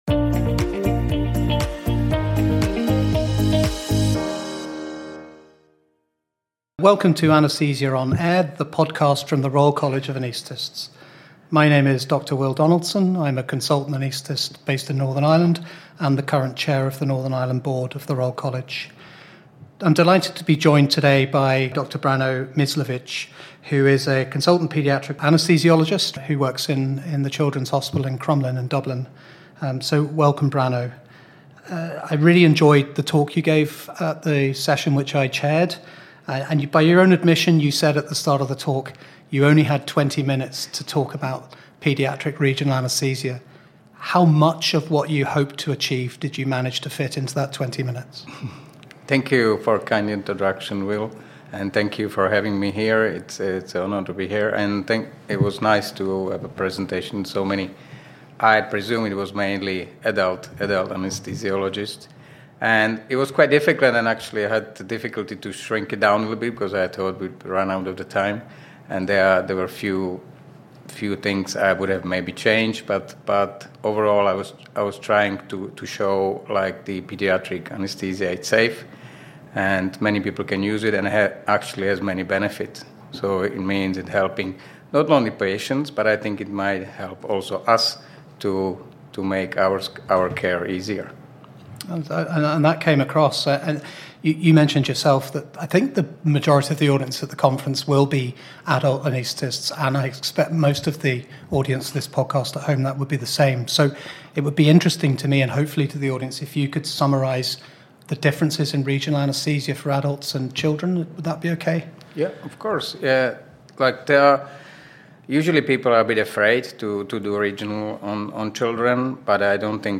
In this podcast, recorded at our Anaesthesia 2025 conference in Belfast consultant anaesthetists